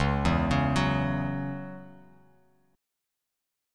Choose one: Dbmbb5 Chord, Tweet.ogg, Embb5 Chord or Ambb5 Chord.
Dbmbb5 Chord